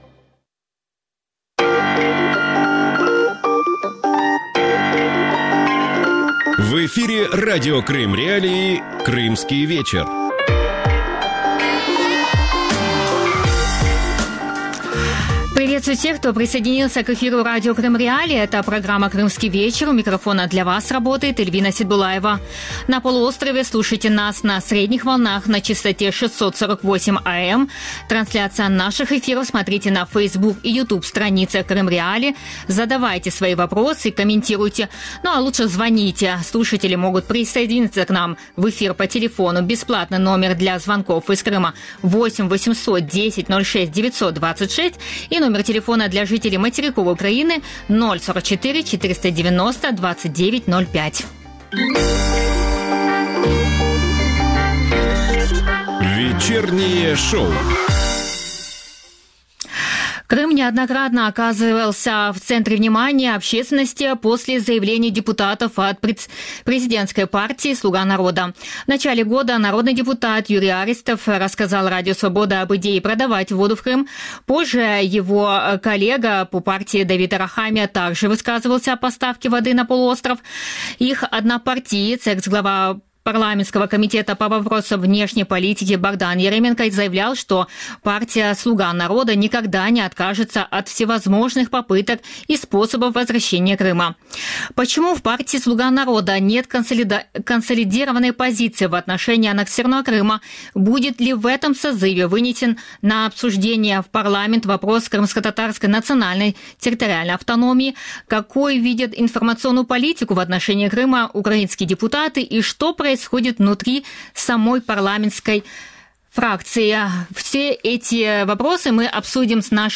Интервью с Лизой Богуцкой | Крымский вечер